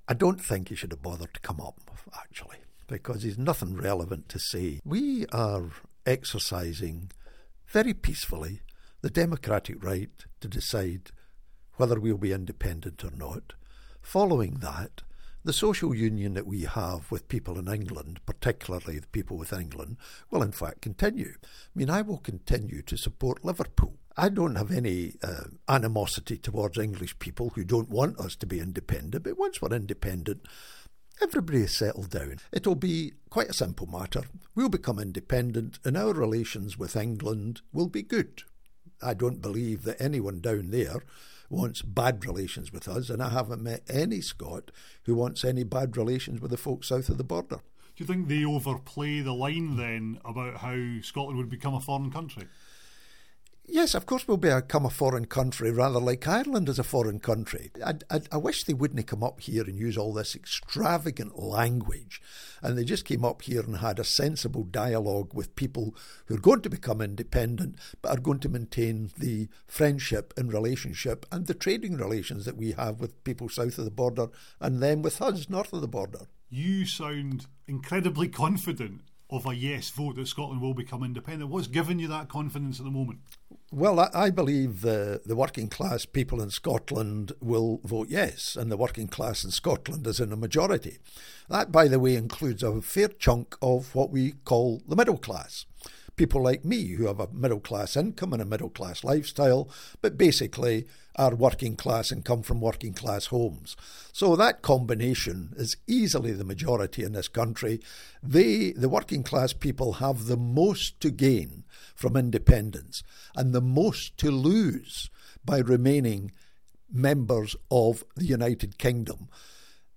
Former SNP Deputy Leader Jim Sillars responds to Sir John Major's comments on Independence.